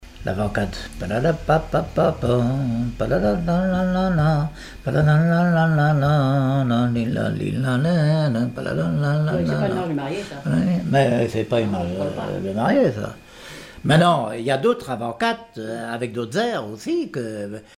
Mémoires et Patrimoines vivants - RaddO est une base de données d'archives iconographiques et sonores.
quadrille : avant-quatre
Témoignage comme joueur de clarinette
Pièce musicale inédite